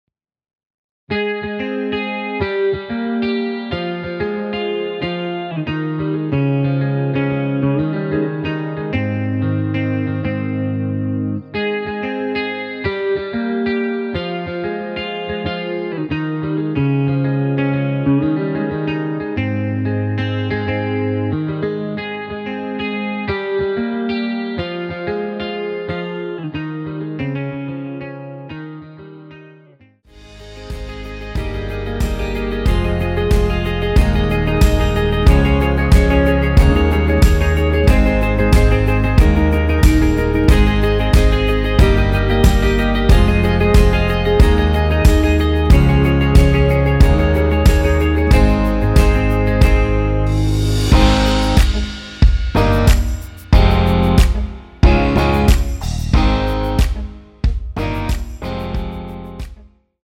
원키에서(-1)내린 (2절 삭제)하고 진행 되는 MR입니다.
Ab
앞부분30초, 뒷부분30초씩 편집해서 올려 드리고 있습니다.
중간에 음이 끈어지고 다시 나오는 이유는